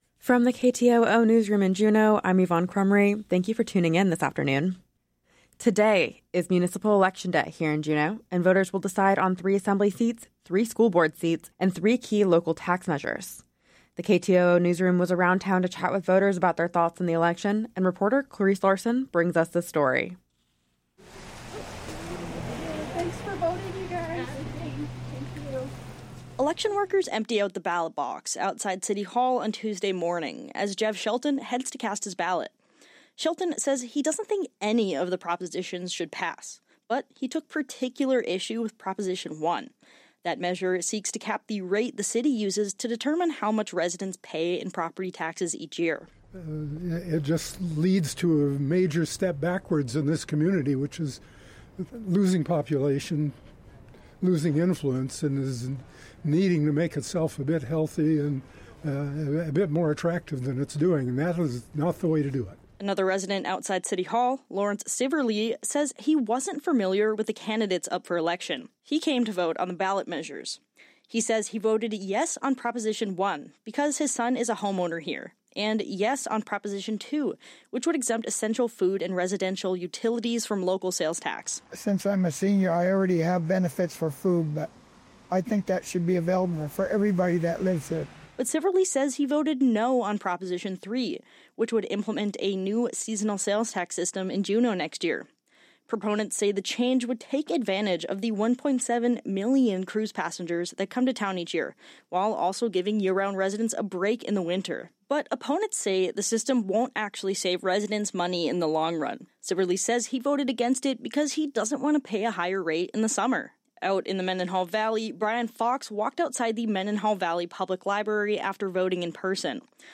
Newscast – Wednesday, Oct. 7, 2025